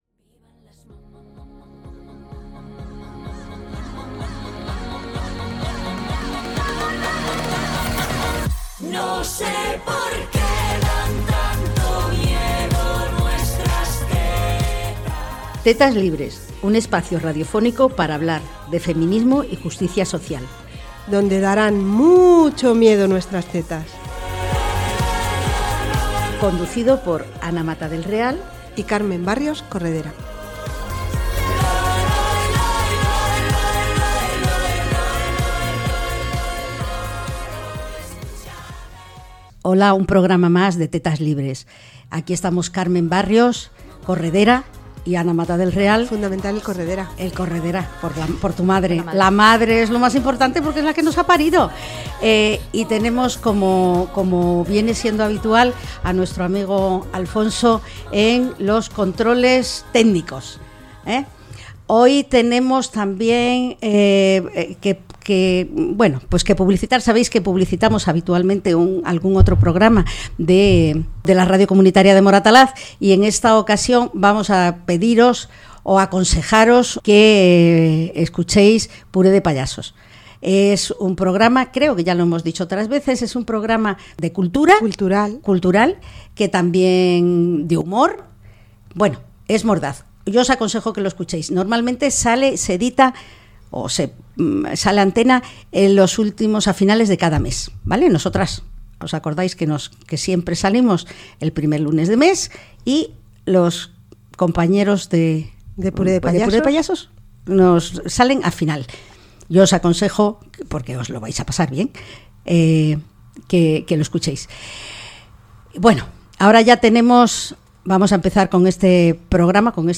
En esta nueva entrevista de Tetas Libres, programa de feminismo y justicia social de Radio Moratalaz